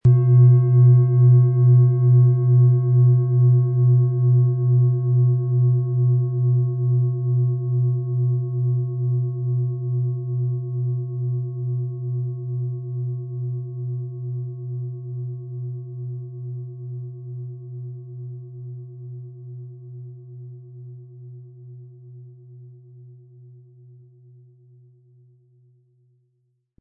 • Mittlerer Ton: Wasser
• Höchster Ton: Chiron
Den passenden Klöppel erhalten Sie umsonst mitgeliefert, er lässt die Schale voll und wohltuend klingen.
PlanetentöneLilith & Wasser & Chiron (Höchster Ton)
MaterialBronze